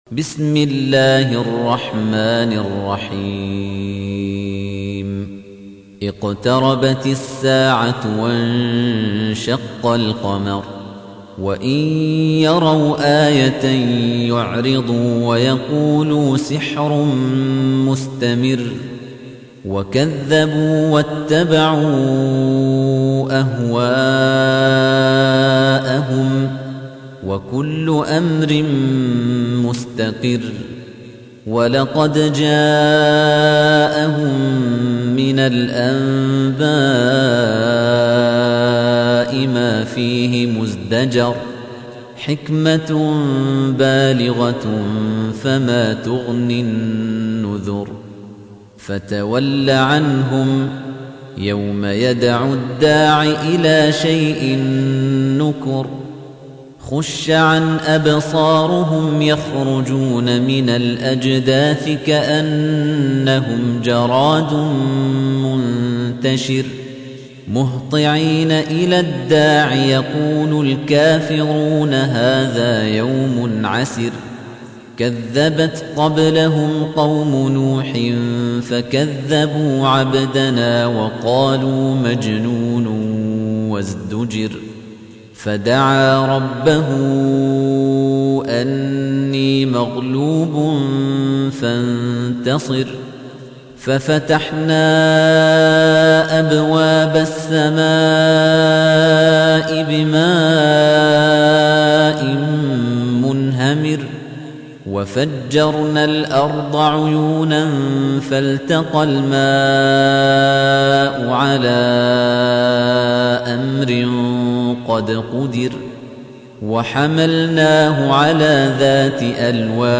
Surah Repeating تكرار السورة Download Surah حمّل السورة Reciting Murattalah Audio for 54. Surah Al-Qamar سورة القمر N.B *Surah Includes Al-Basmalah Reciters Sequents تتابع التلاوات Reciters Repeats تكرار التلاوات